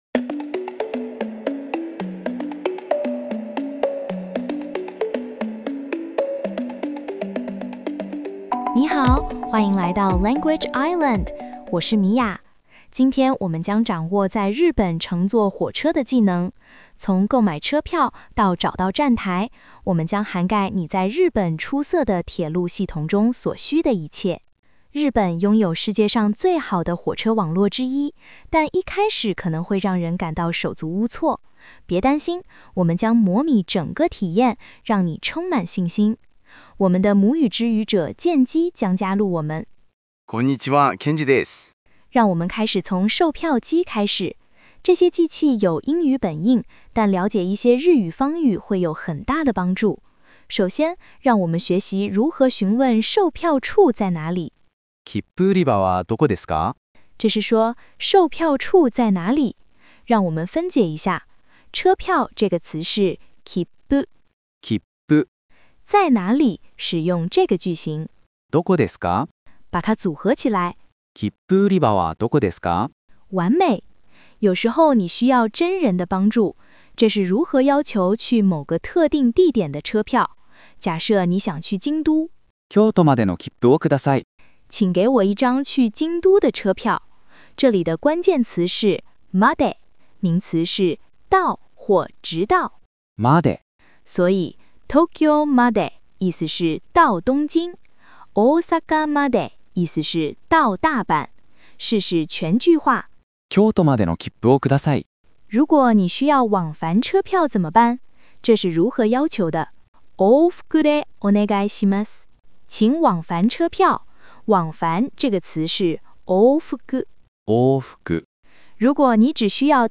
JapaneseLearn JapaneseJapanese languageJapanese phrasesLanguage IslandChinese narrationTravelTrain travelKippuKyoto